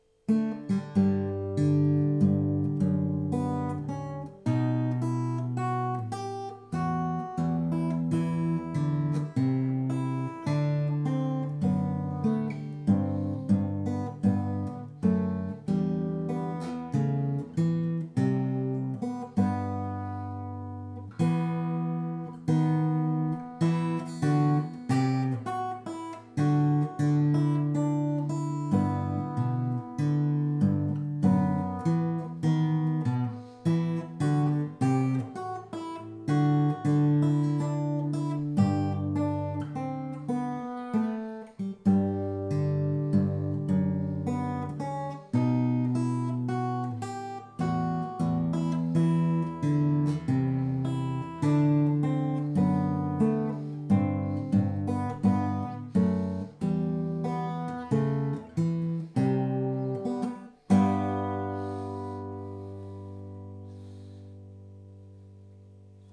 Immer jeweils Verse + Bridge + Vers.